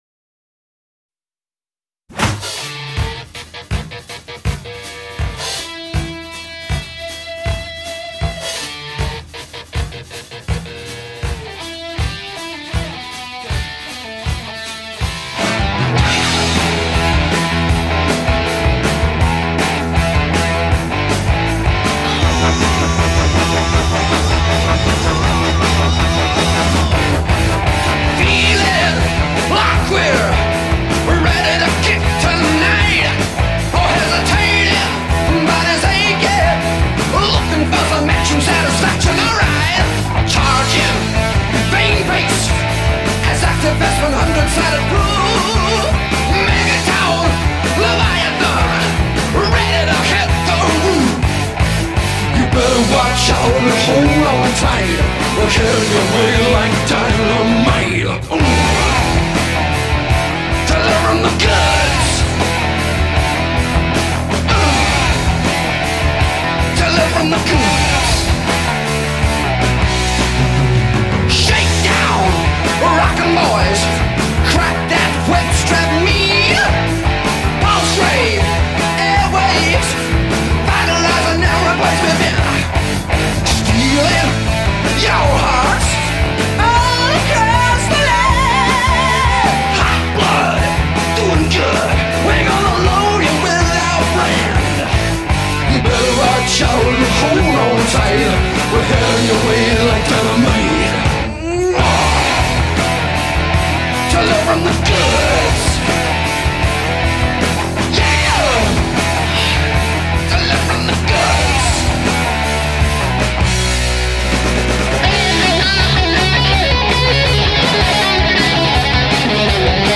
Heavy Metal, Hard Rock